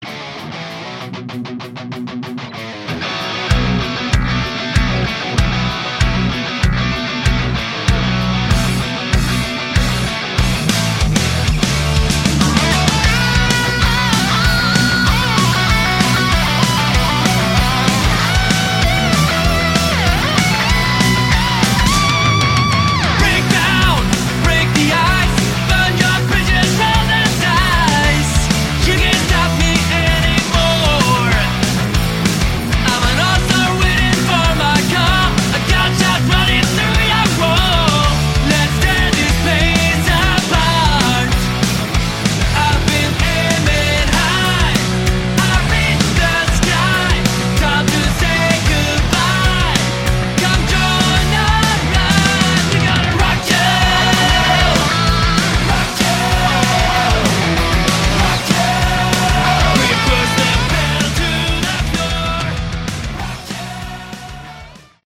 Category: Melodic Metal
vocals, guitar
bass
drums